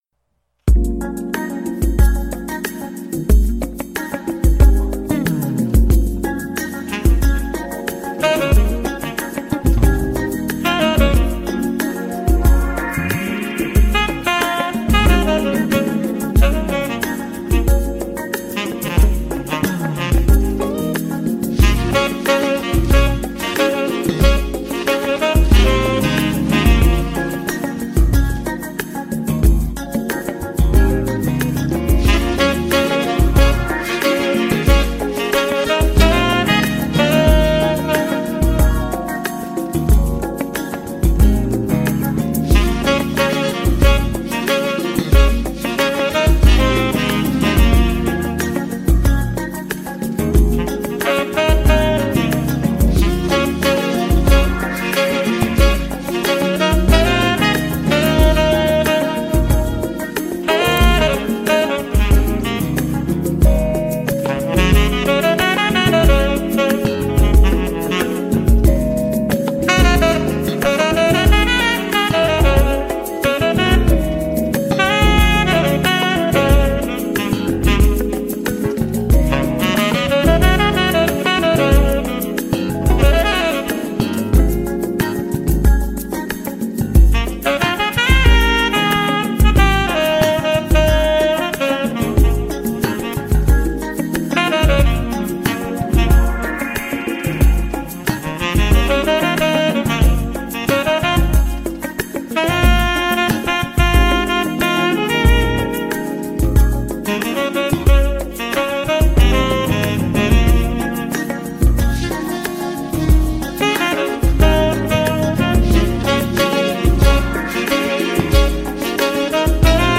Why not browse the many other interviews on this site and get to know the back stories to the artists you know and love